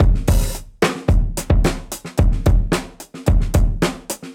Index of /musicradar/dusty-funk-samples/Beats/110bpm
DF_BeatB_110-01.wav